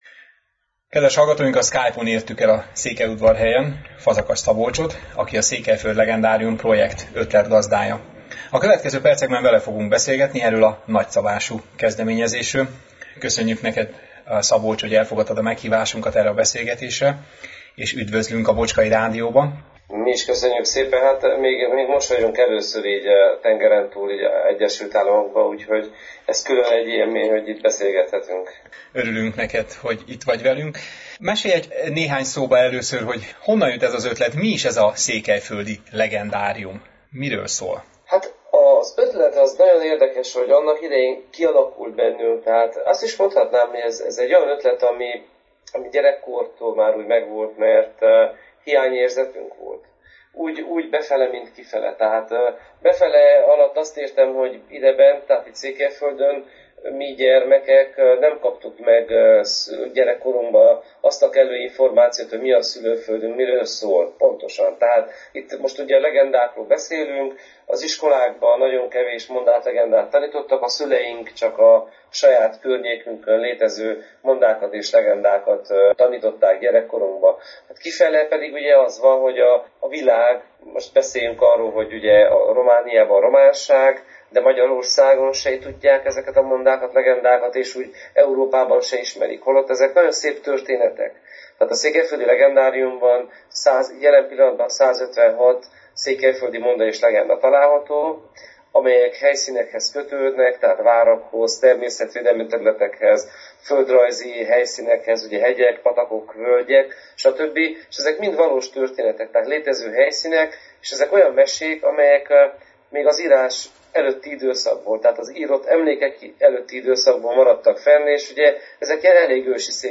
Az interjú meghallgatható a vasárnapi élőadás után.